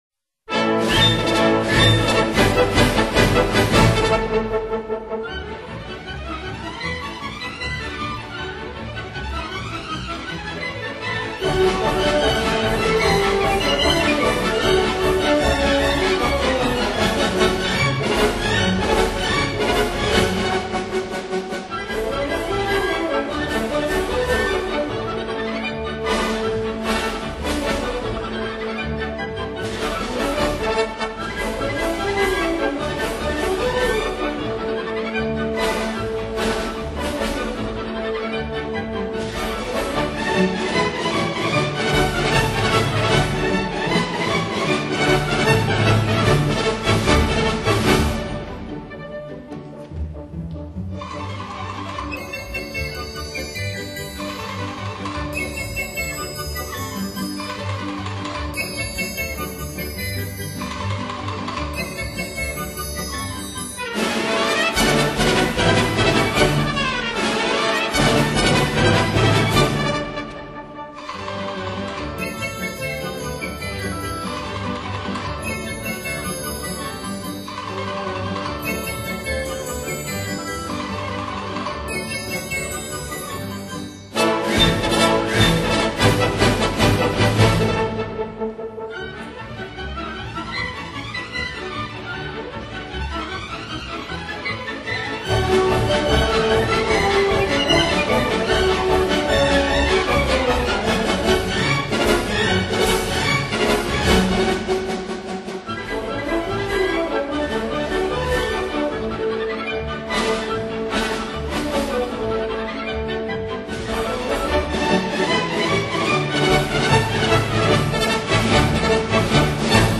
音乐类型:独幕芭蕾舞剧